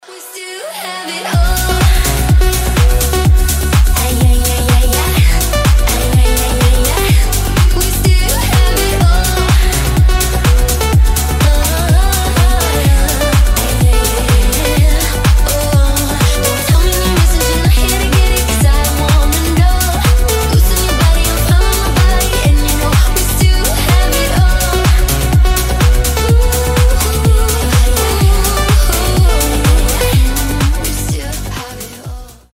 • Качество: 320, Stereo
EDM
progressive house